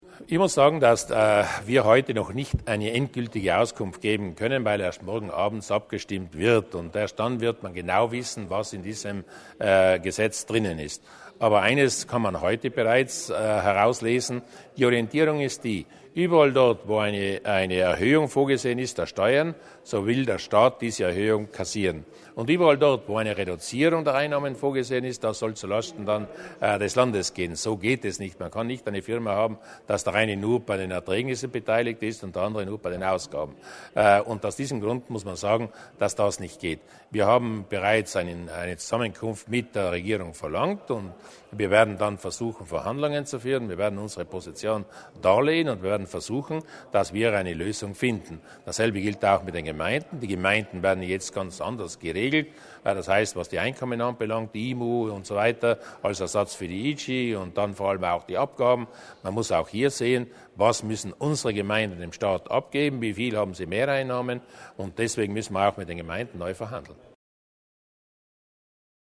Landeshauptmann Durnwalder über die nächsten Schritte bezüglich Haushalt und Finanzgesetz